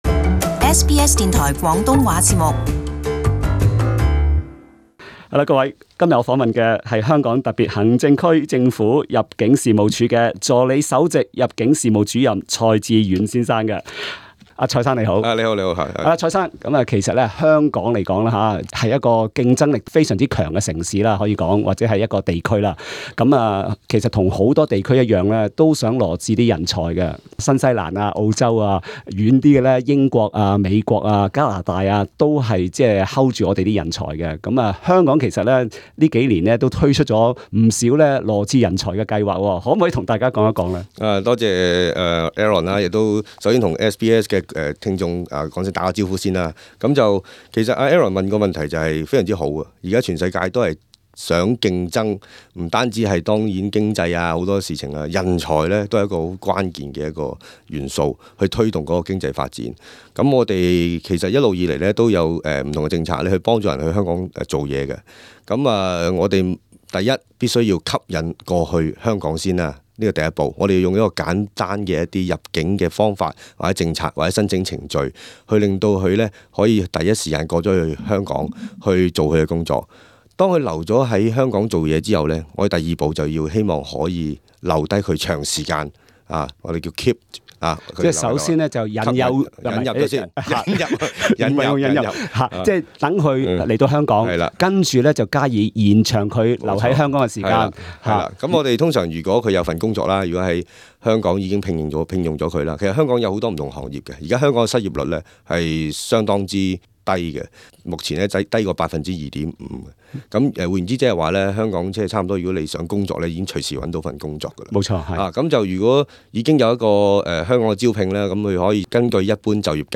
在這第一輯訪問中